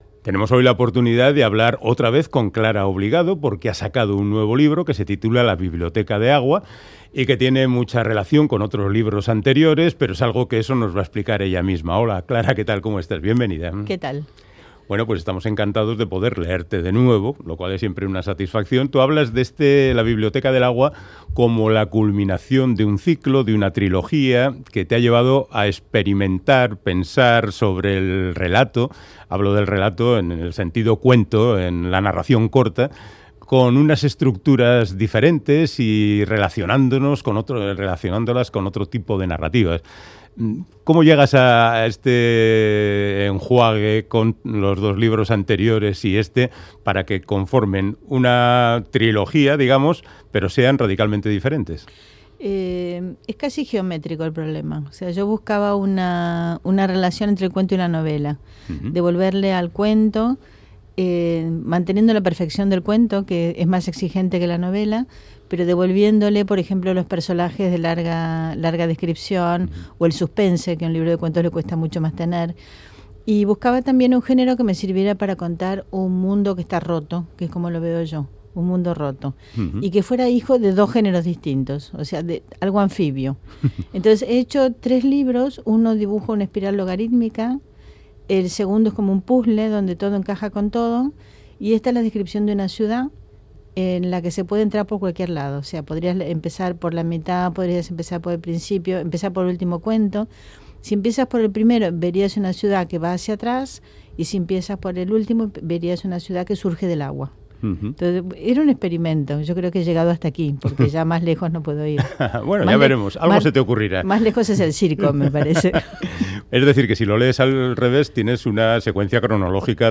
Audio: Hablamos con Clara Obligado de su libro de relatos La biblioteca del agua